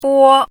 汉字“玻”的拼音是：bō。
“玻”读音
国际音标：po˥
bō.mp3